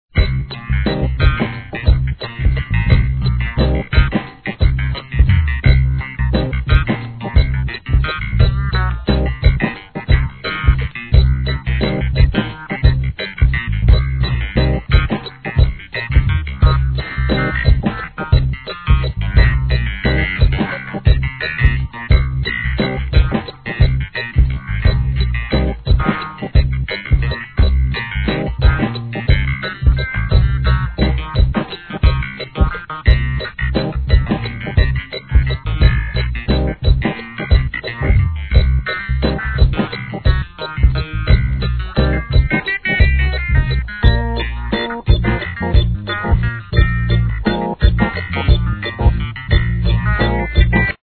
HIP HOP/R&B
FUNK〜ROCK〜SKAまでを飲み込んだ音楽性でリリースの傑作、1994年1st!!